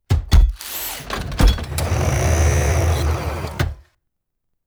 ExitShip.wav